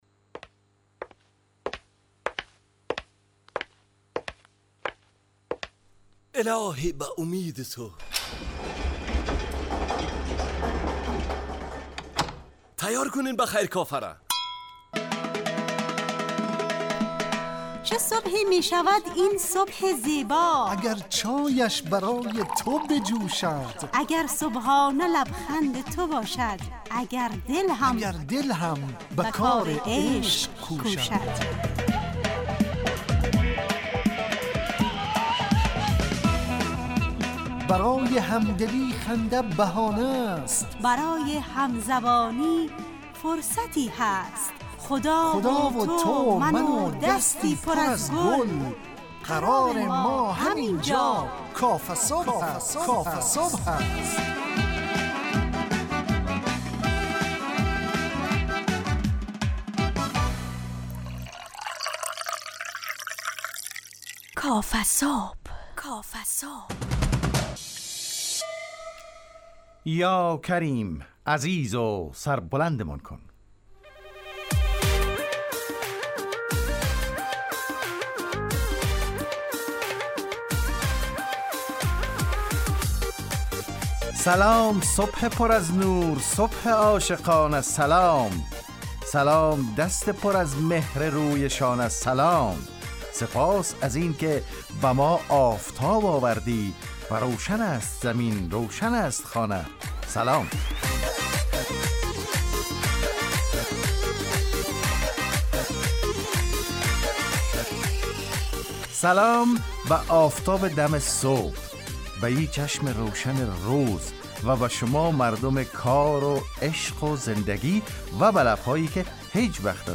کافه‌صبح – مجله‌ی صبحگاهی رادیو دری با هدف ایجاد فضای شاد و پرنشاط صبحگاهی